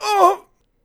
hurt2.wav